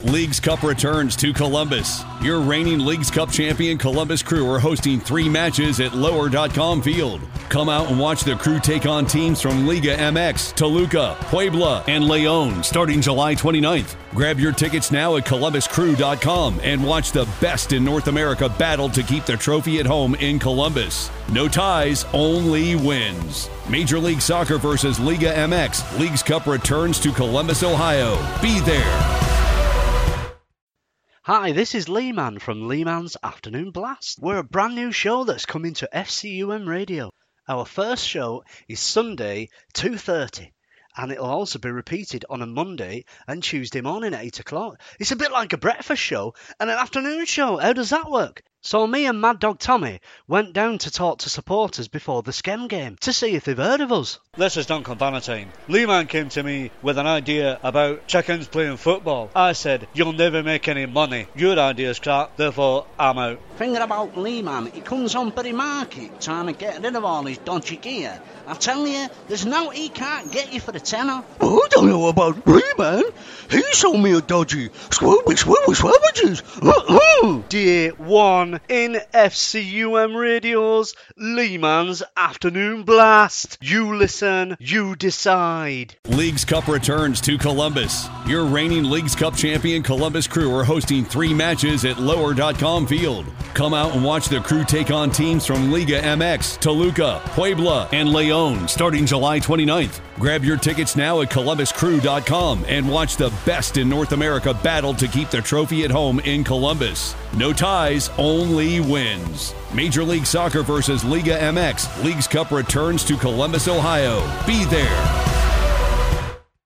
Advert